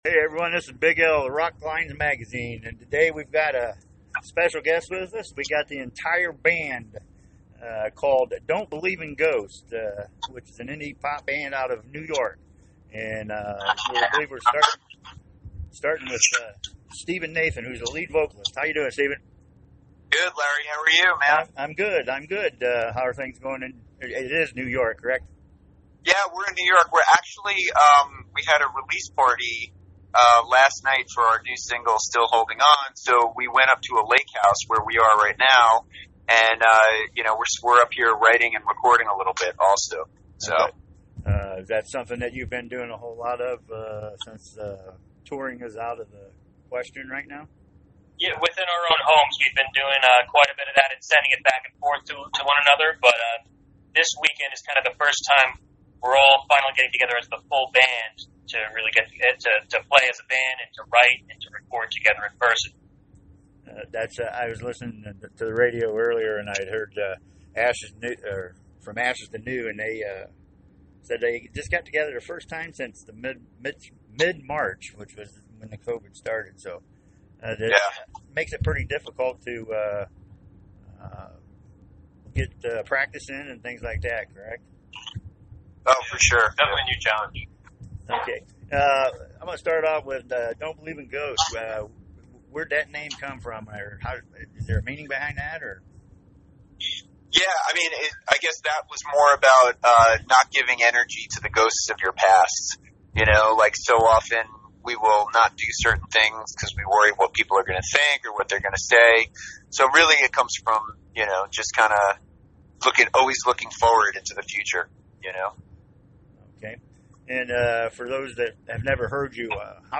Also check out the link below to hear my interview with the band. https